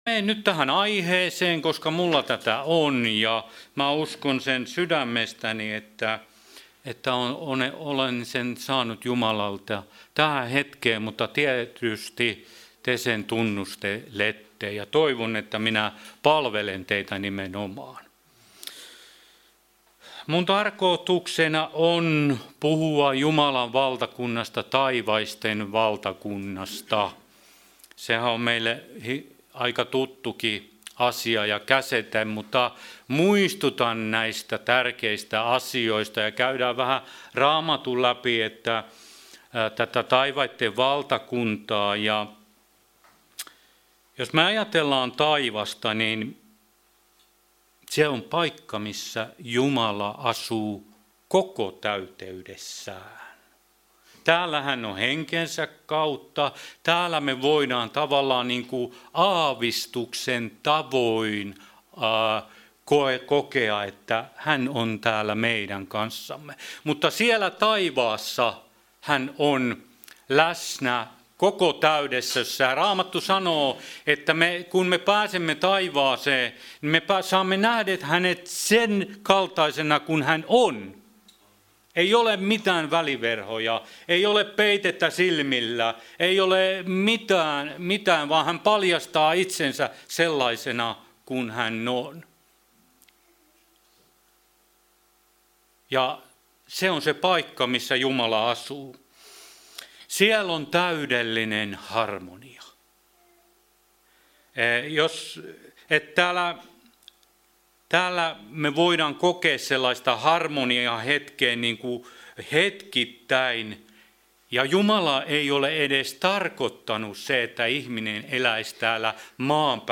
Service Type: Jumalanpalvelus